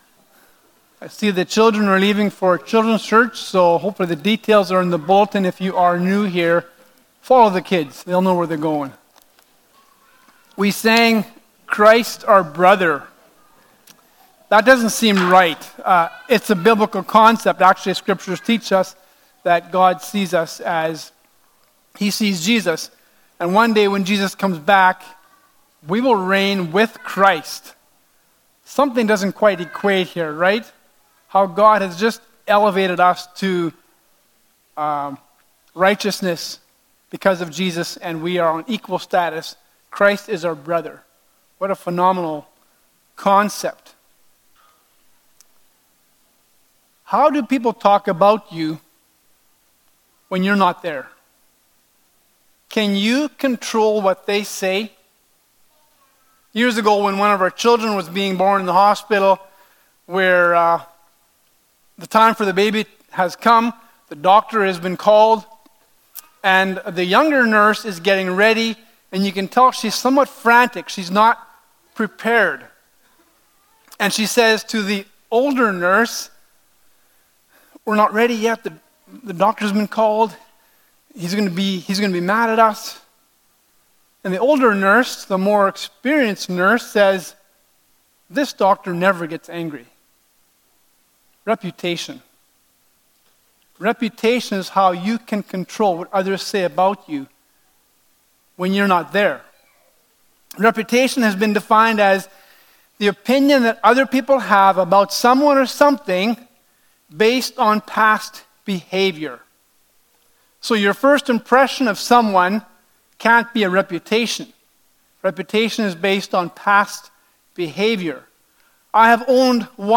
Acts 16:1-5 Service Type: Sunday Morning « Lydia